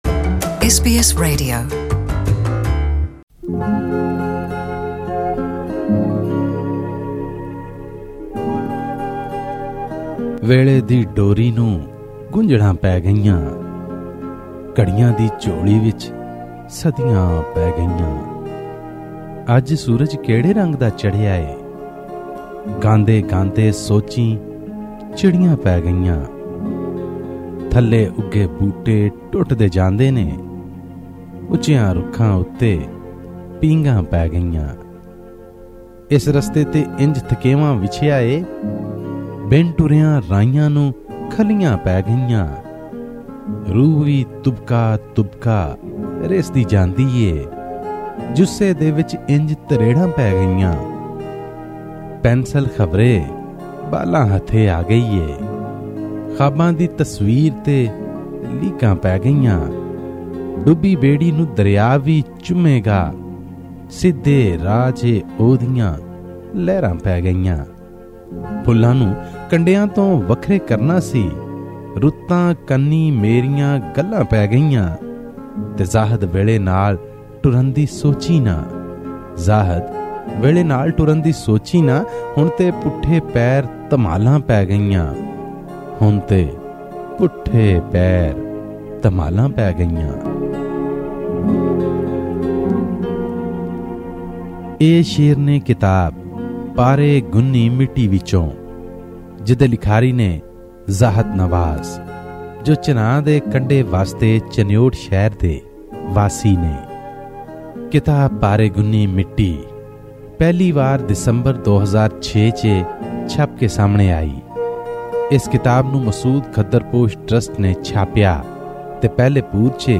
Hear a literary critique of the works of well-known Punjabi poet in this special presentation from Lahore (Pakistan).